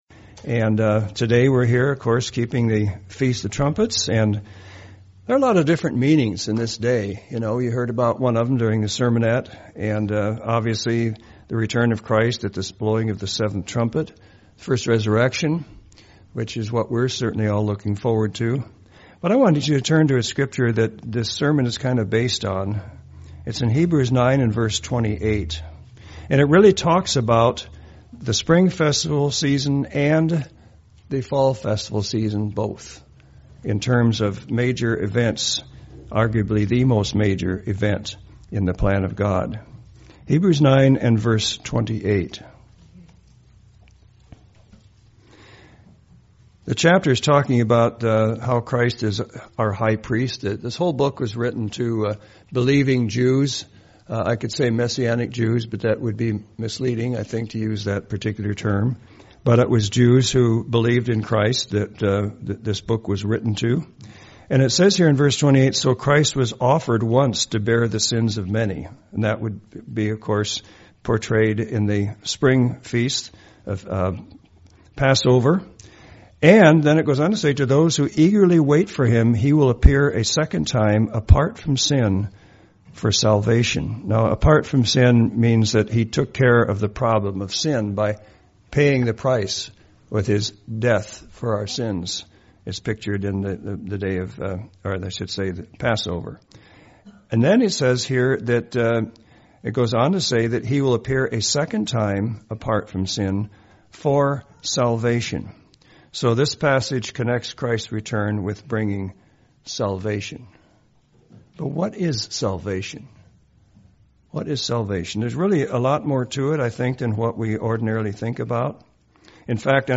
In this sermon we take a look at the meaning of salvation and the return of Christ.
Given in Medford, OR